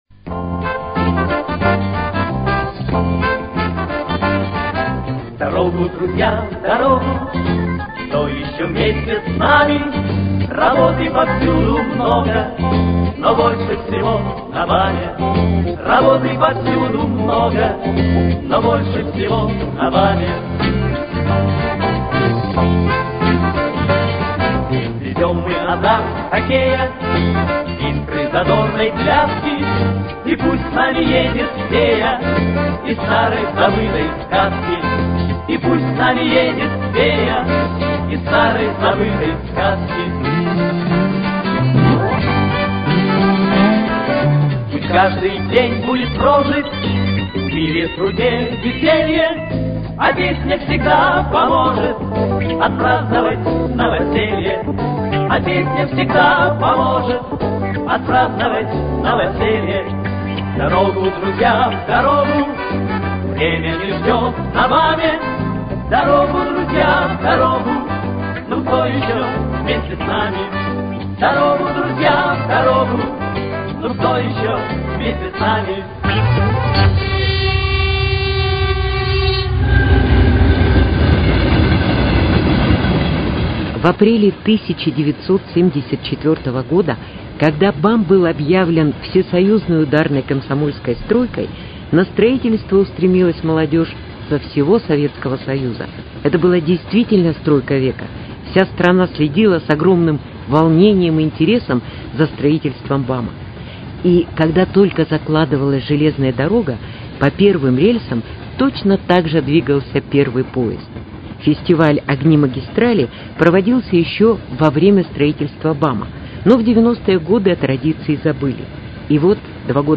Из коллекции радиоканала: Репортаж с Фестиваля "Огни магистрали"